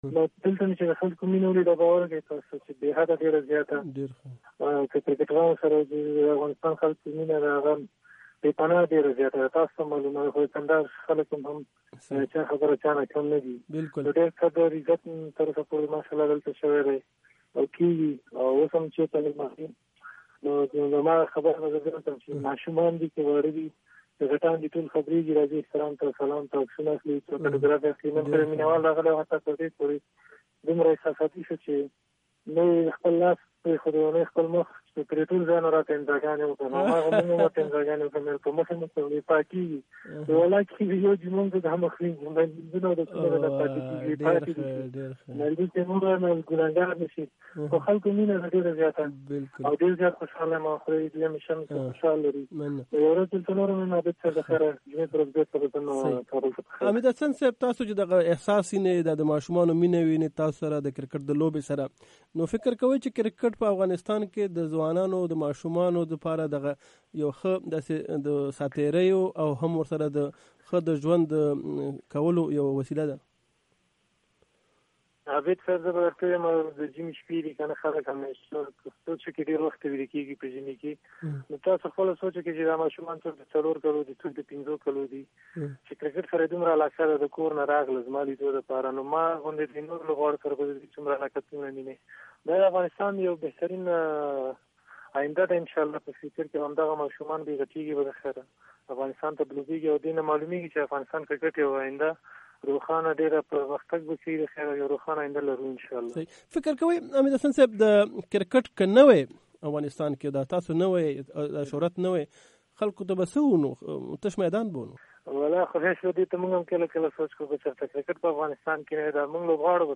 مرکې
د حمید حسن بشپړه مرکه په لاندیني لینک کې اوریدی شئ